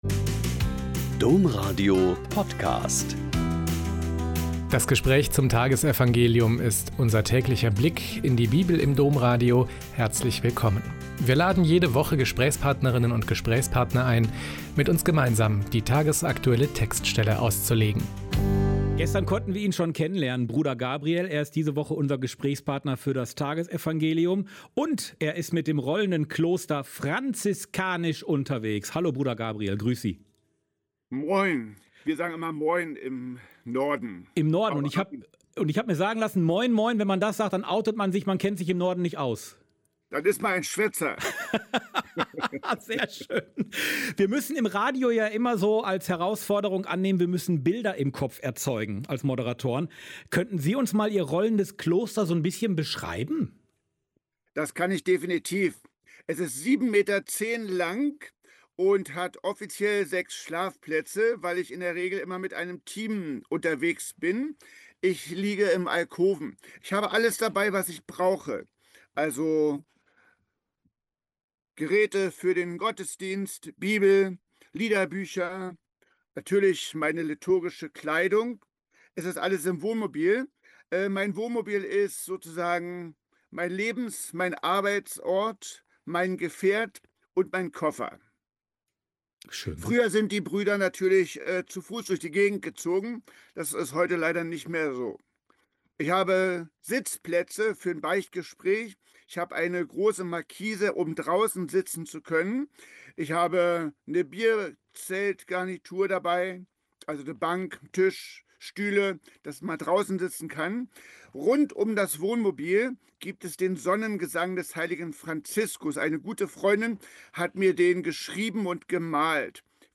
Mt 14,22-36 - Gespräch